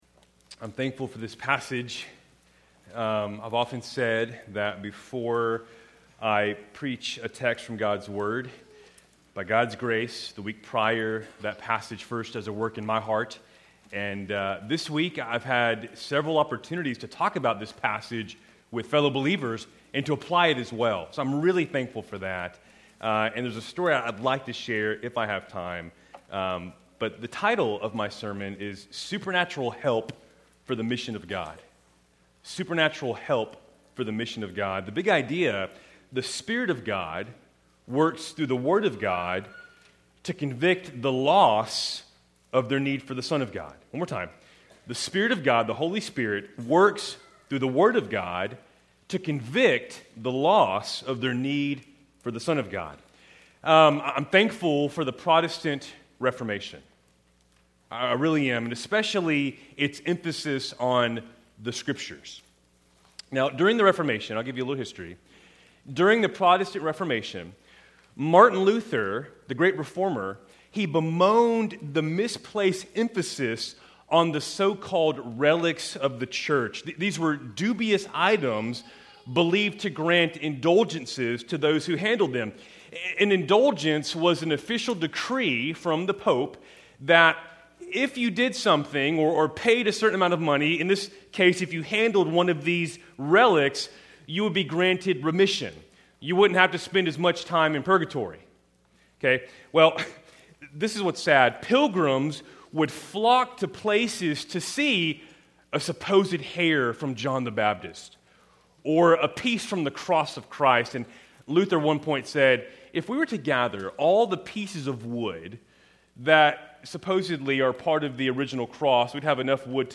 Keltys Worship Service, October 12, 2025
How are the three points from today’s sermon related?